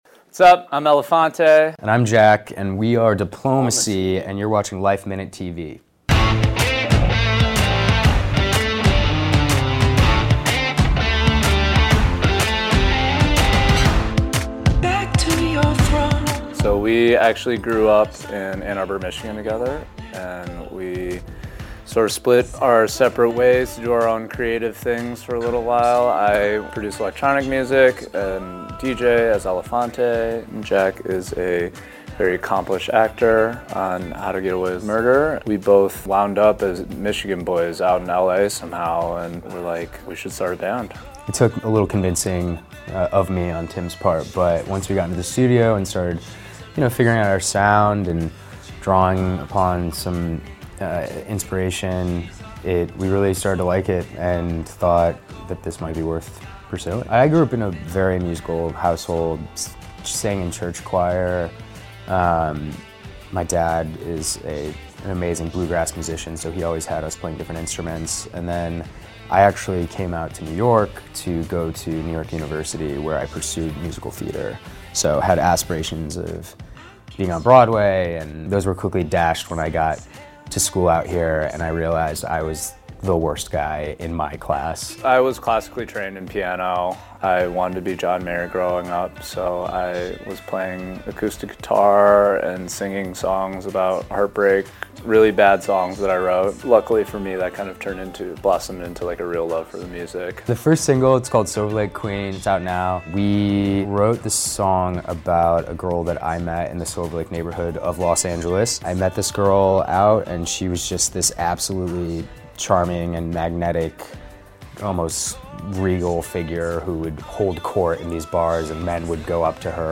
The duo stopped by our studio to talk about their sexy debut single “ Silver Lake Queen ,” what’s next for the band and their solo projects.